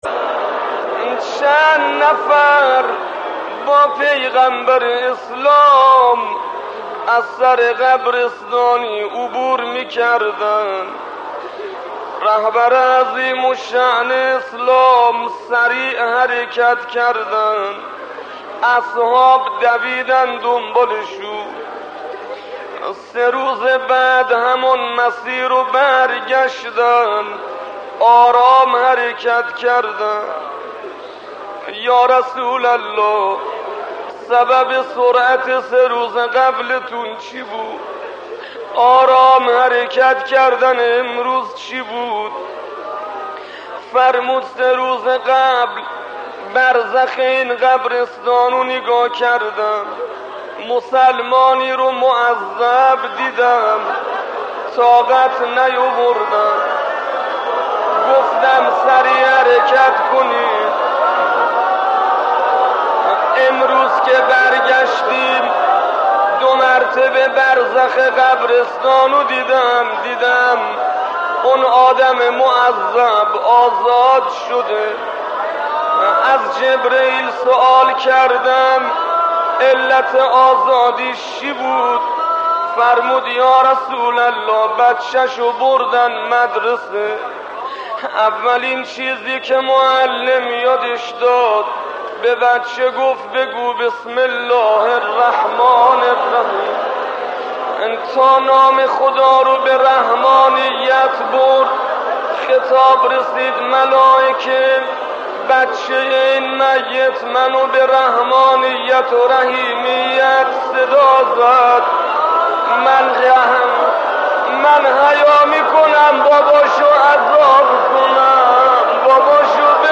داستانهای شنیدنی در مورد پیامبر اکرم صلی الله علیه و آله در بیانات استاد آیت الله شهید سید عبدالحسین دستغیب رحمه الله
دسترسی به مطالب: سخنرانی های شهید دستغیب داستانهای شنیدنی پیامبر اکرم صلوات الله علیه وآله از شهید دستغیب